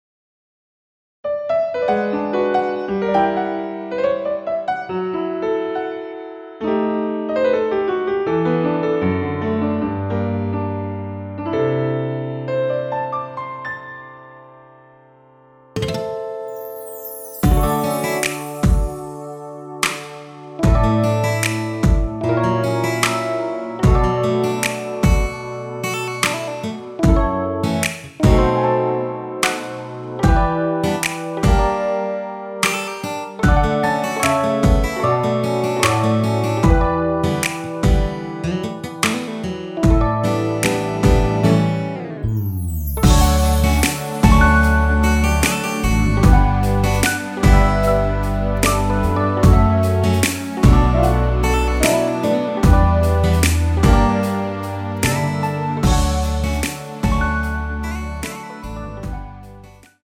원키에서(-1)내린 MR입니다.
앨범 | O.S.T
◈ 곡명 옆 (-1)은 반음 내림, (+1)은 반음 올림 입니다.
앞부분30초, 뒷부분30초씩 편집해서 올려 드리고 있습니다.
중간에 음이 끈어지고 다시 나오는 이유는